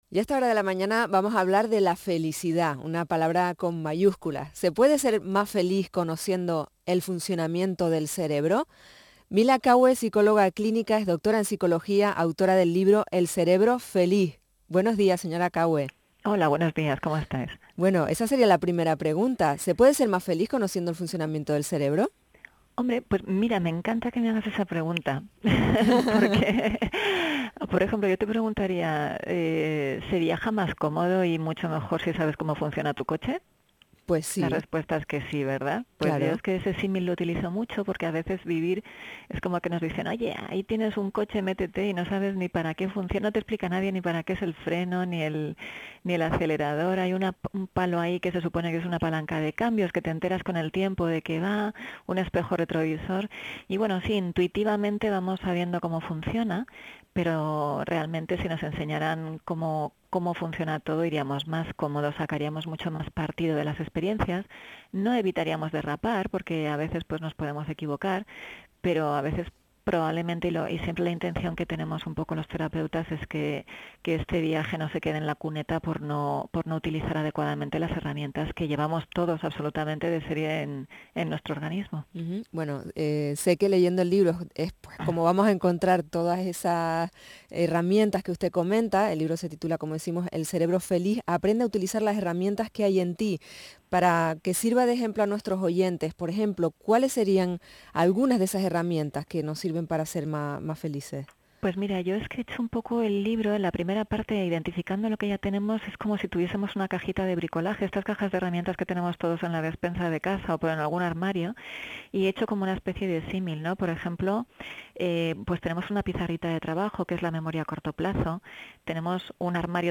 Pues os invito a que sigáis escuchando esta deliciosa entrevista en Canarias Radio, que mantuvimos el pasado 15 de Noviembre.